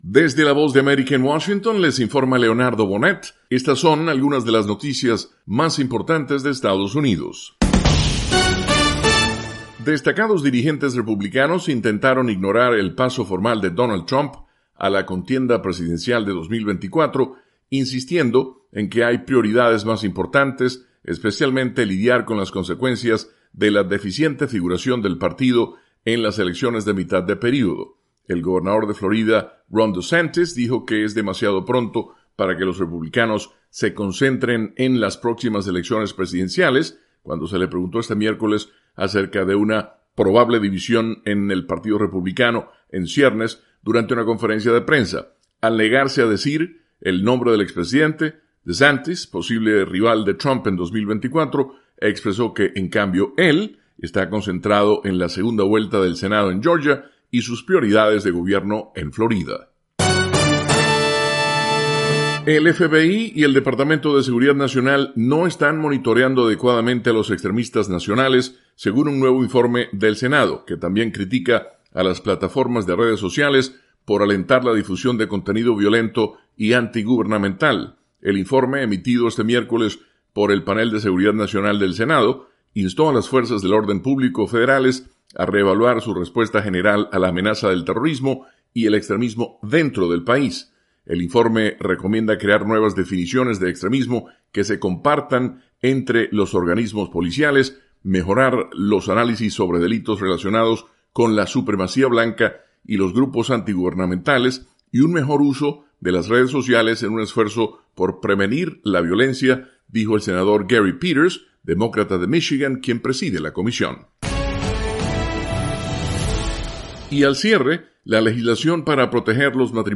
Resumen con algunas de las noticias más importantes de Estados Unidos